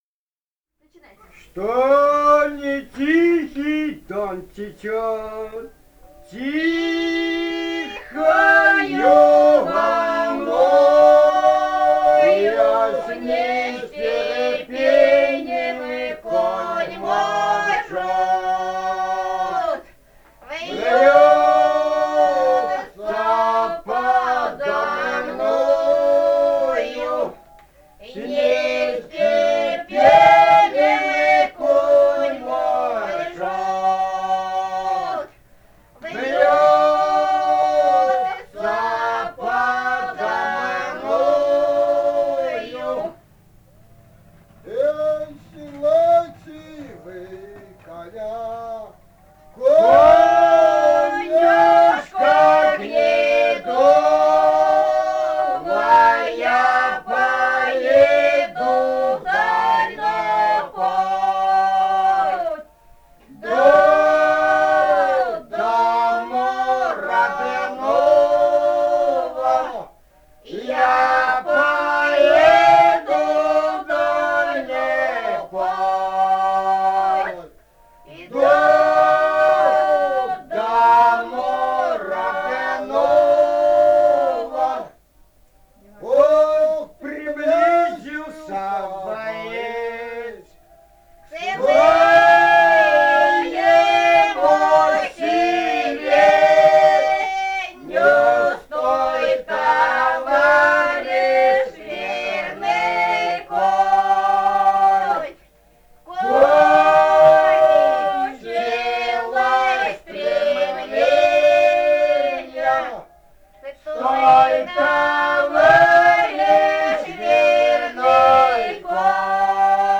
Этномузыкологические исследования и полевые материалы
«Что не тихий Дон течёт» (лирическая «казачья»).
Бурятия, с. Харацай Закаменского района, 1966 г. И0905-06